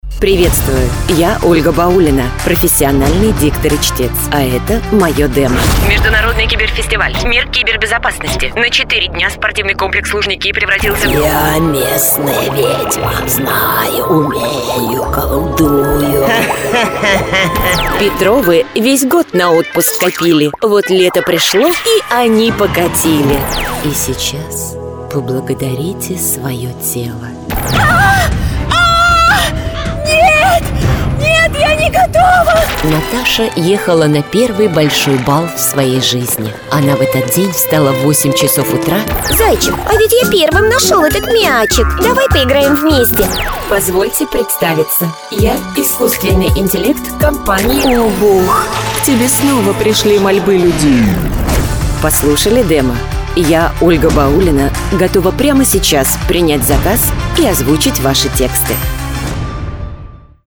Пример звучания голоса
Жен, Рекламный ролик/Средний
Микрофон SCARLETT CM25 Звуковая карта FOCUSRITE Дикторская кабина